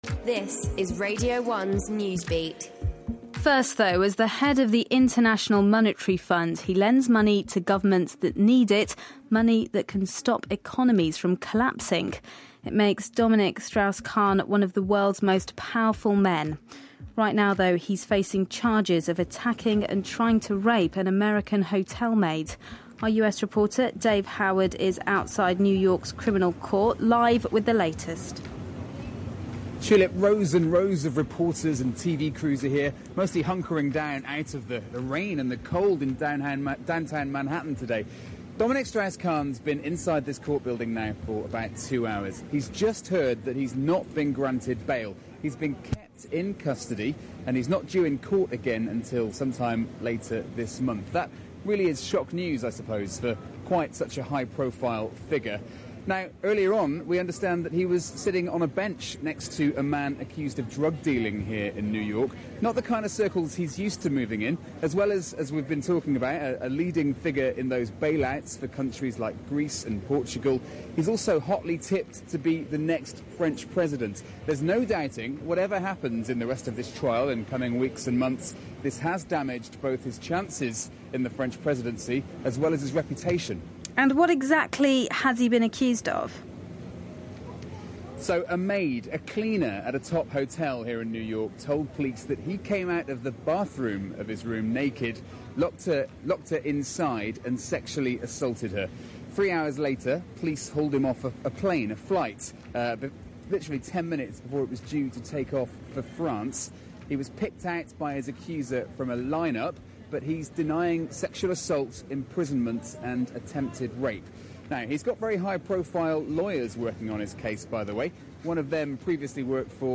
Dominique Strauss Kahn refused bail - live from outside New York's criminal court